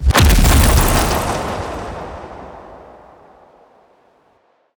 FeySabDecoyBlastA.ogg